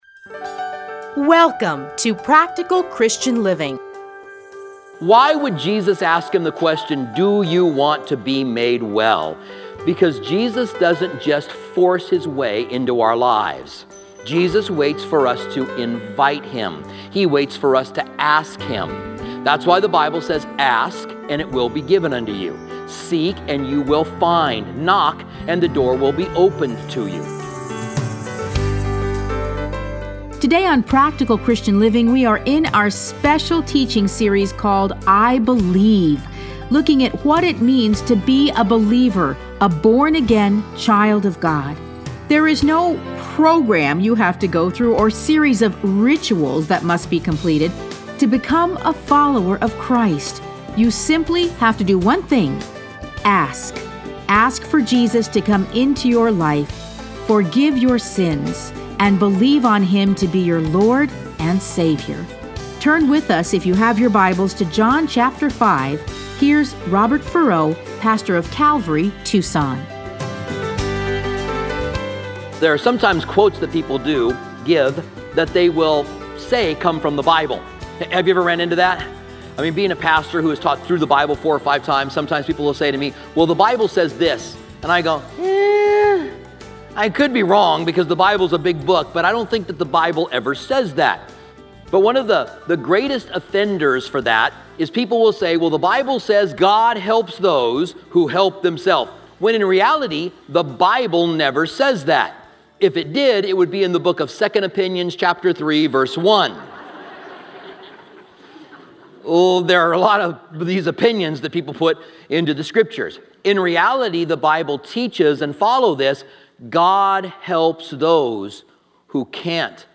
Listen to a teaching from John 5:1-30.